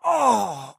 /public-share/packwiz-modpacks/tbm-minecraft-mods/resourcepacks/TBMPack/assets/minecraft/sounds/mob/silverfish/
hit3.ogg